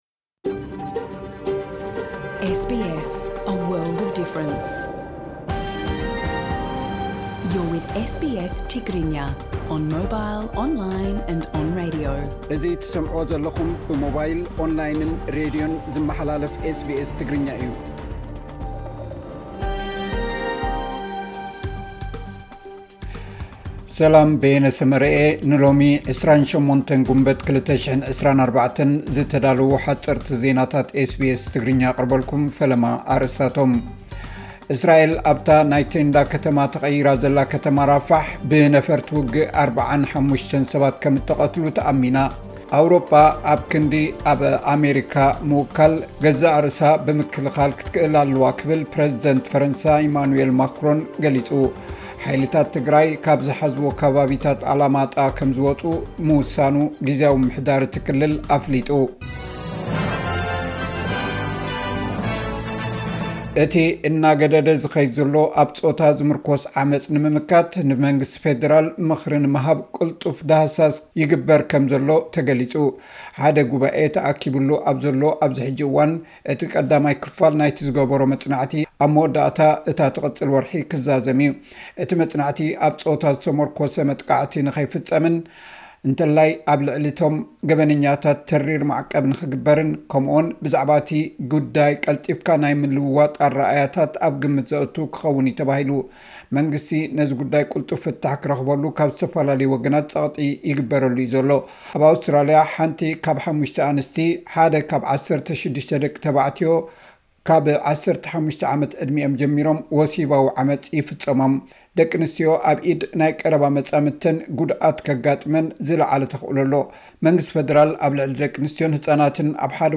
ዕለታዊ ዜና ኤስ ቢ ኤስ ትግርኛ (28 ግንቦት 2024)